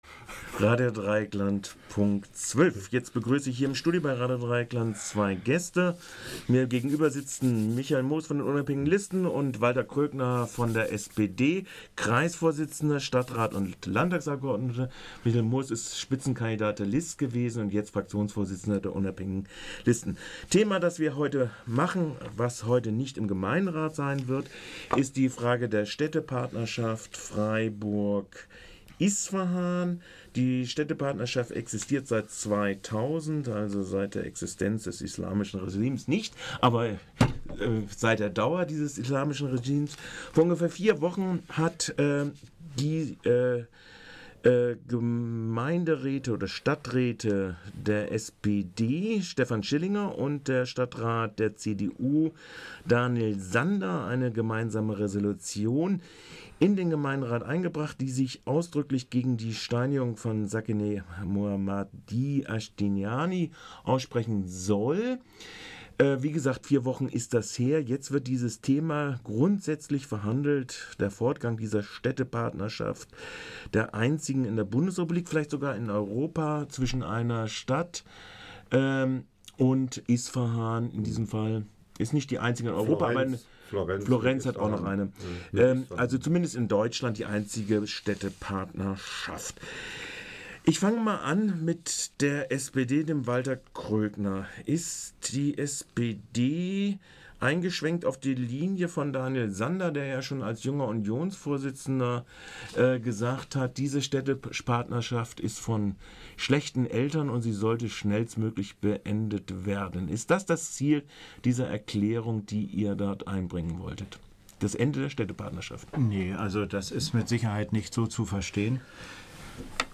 20.05.2009Gäste der heutigen Studiodebatte